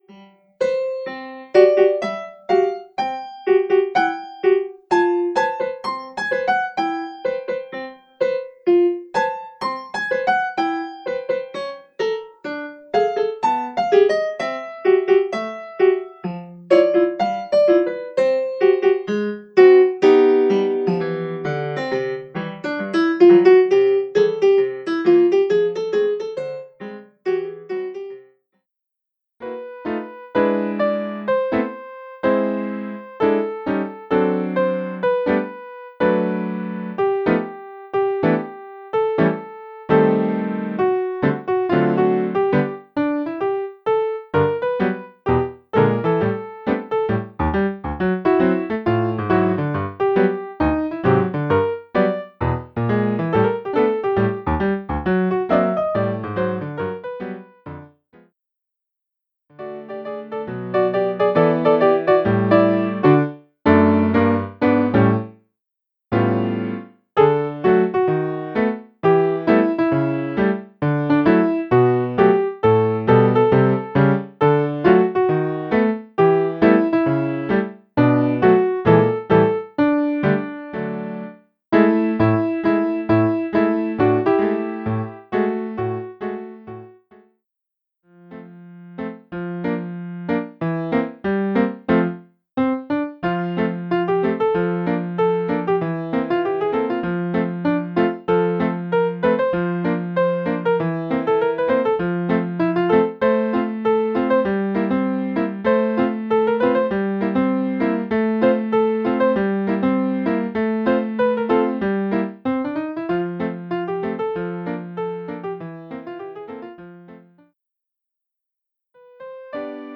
Piano Solo